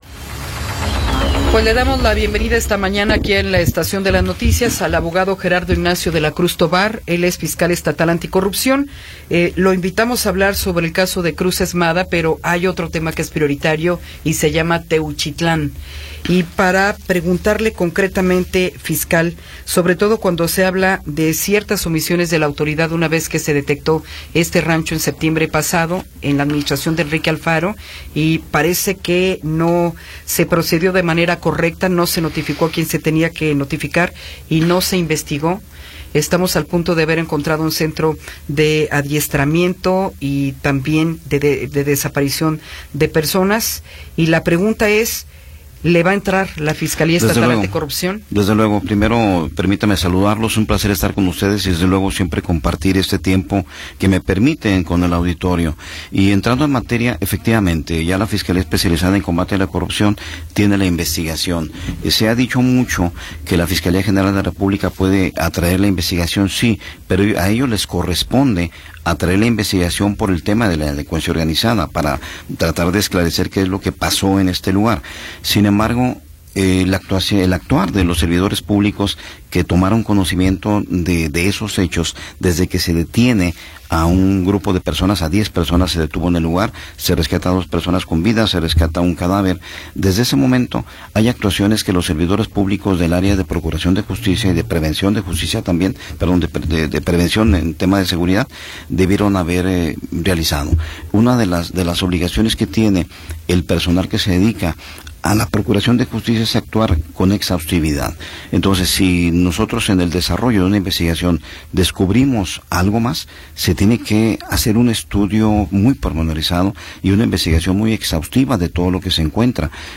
Entrevista con Gerardo Ignacio de la Cruz Tovar